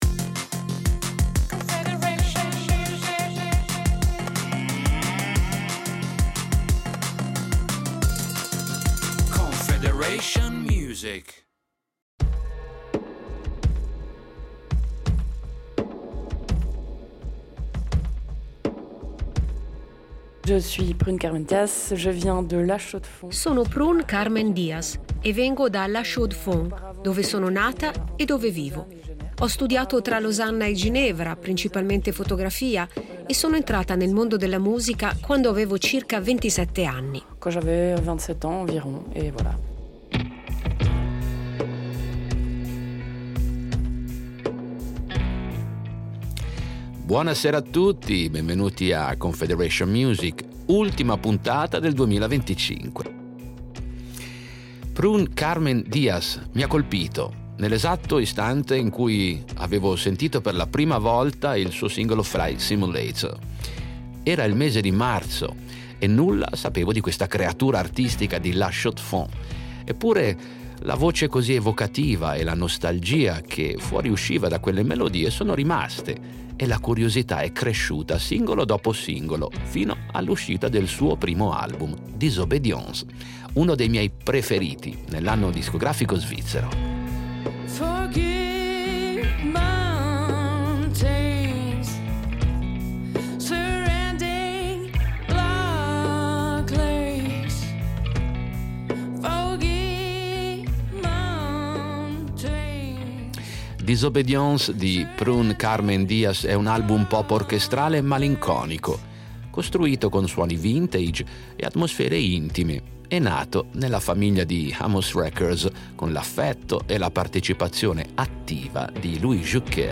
Musica pop
La sua voce così evocativa